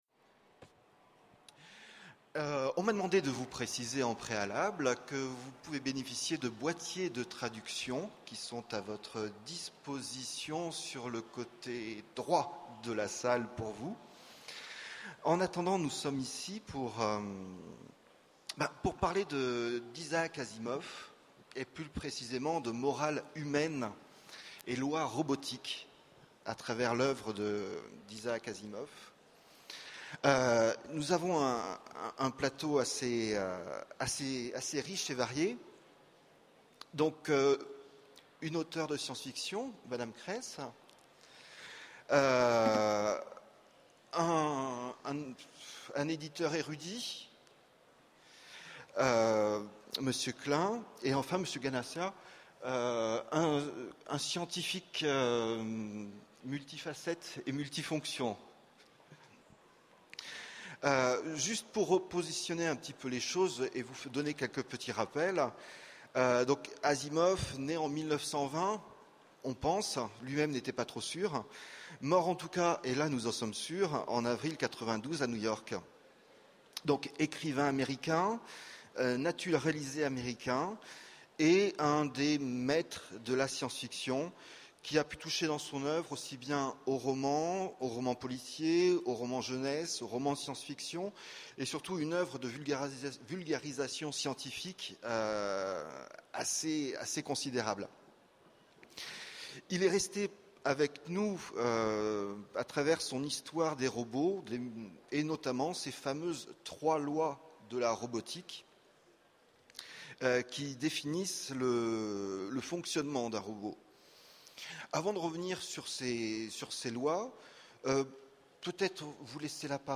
Utopiales 12 : Conférence Morales humaines et lois robotiques dans l’oeuvre d’Isaac Asimov
Conférence